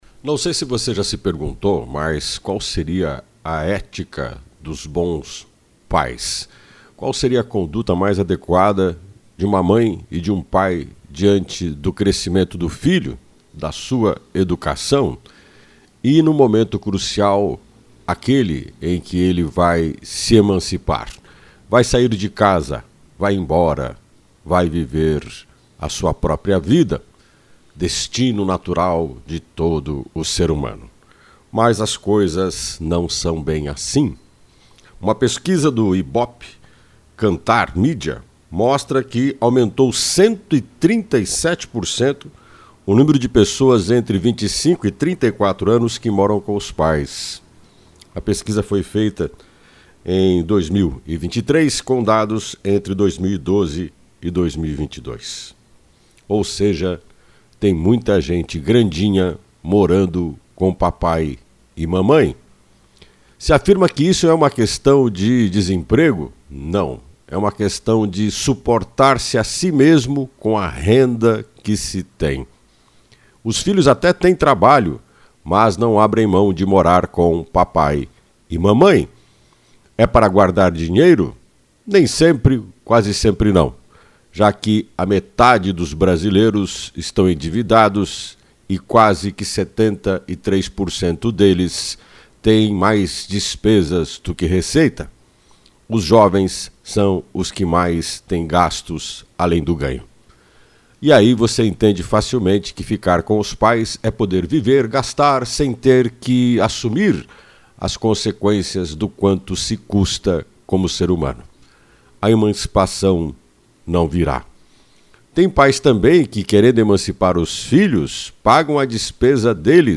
Opinião